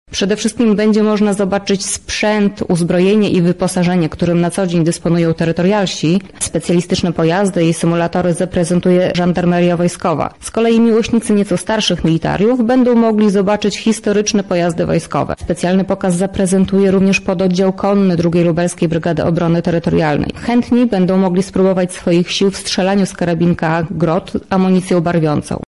O godz. 13 zapraszamy na Błonia zamkowe, gdzie odbędzie się piknik wojskowy– mówi kpt.